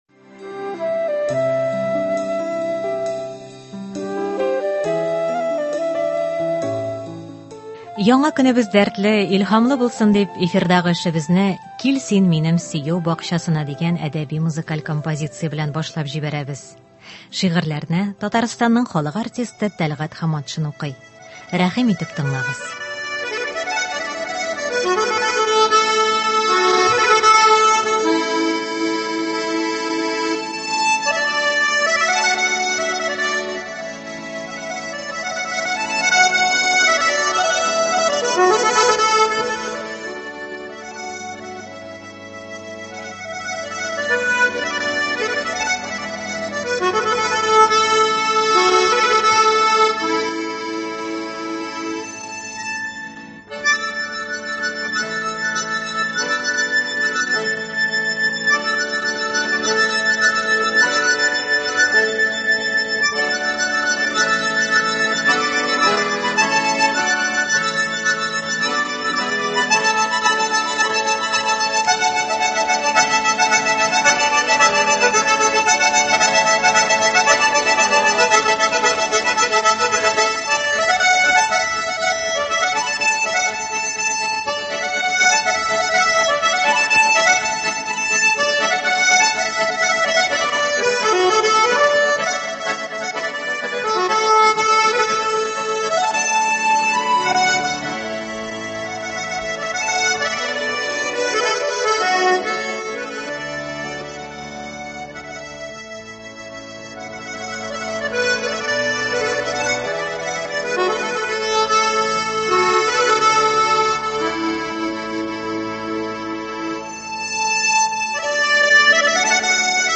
Әдәби-музыкаль композиция (21.08.2021)